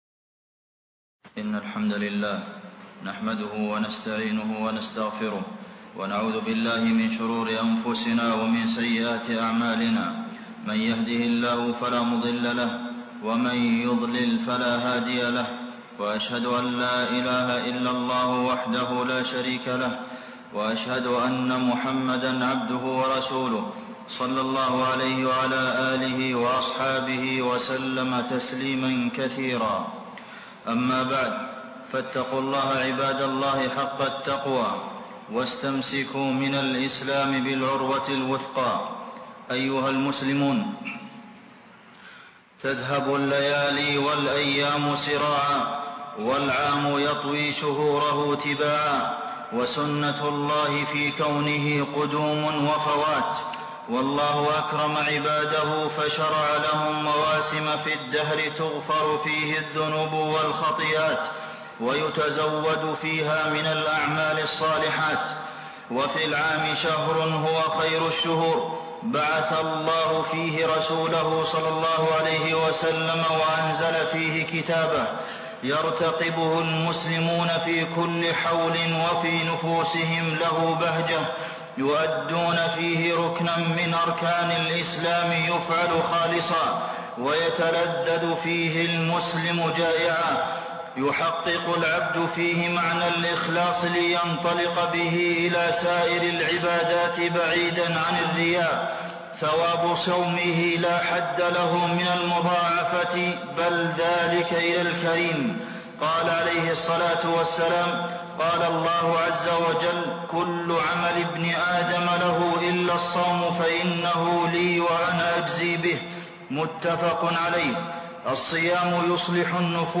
الاستعداد لرمضان (خطبة جمعة) - الشيخ عبد المحسن القاسم